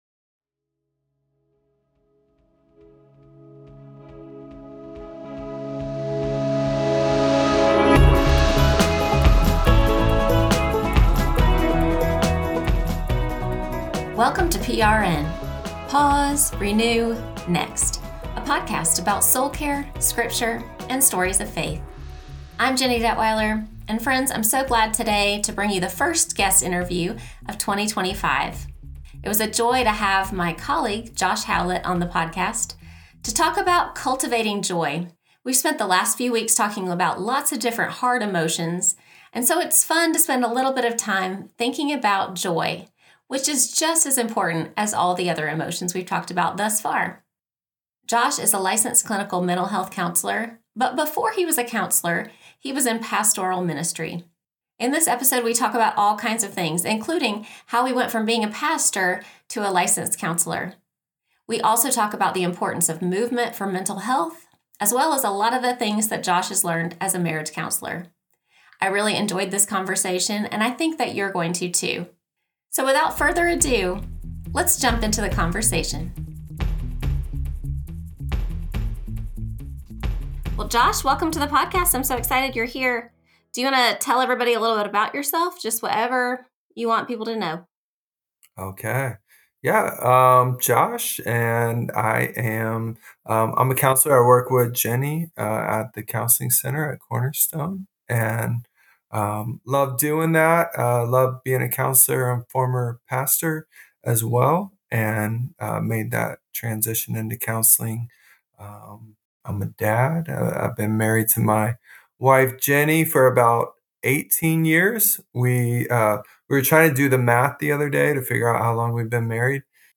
In our conversation, he tells the story of how he transitioned into the counseling field.